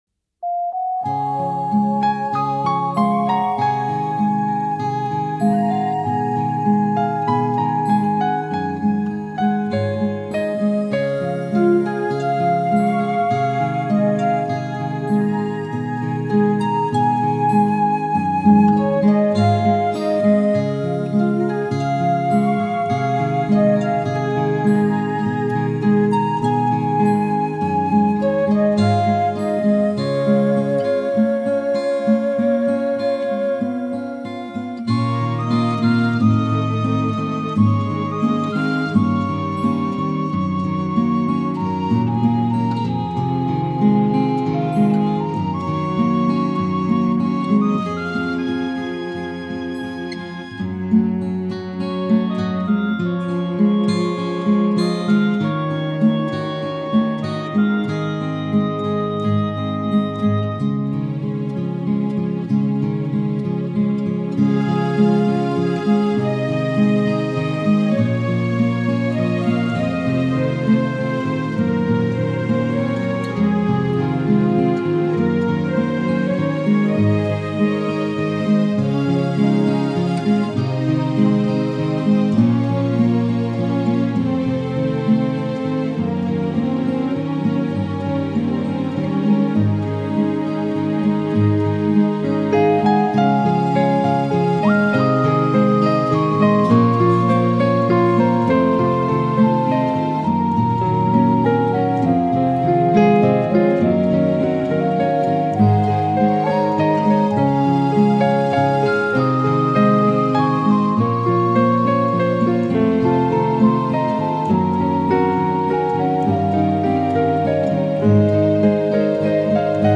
You Were There Instrumental 2012
you-were-there-instrumental.mp3